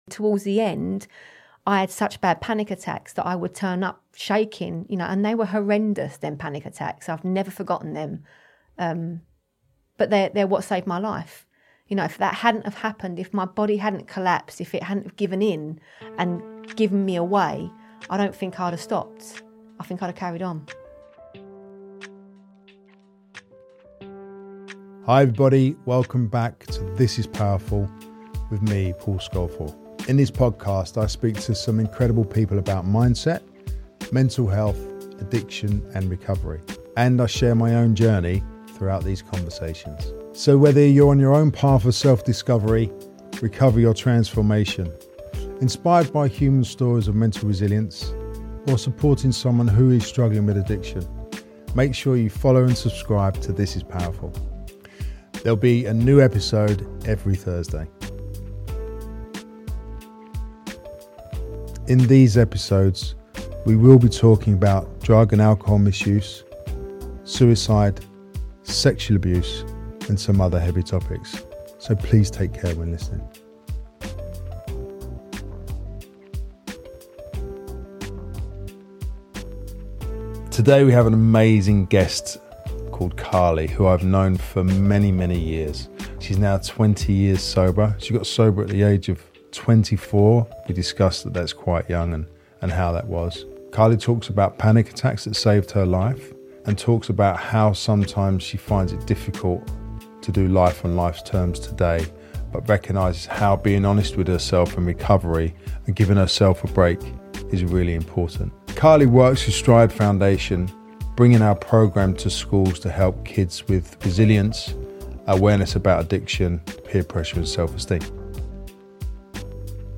Join us in a conversation about the power of self-acceptance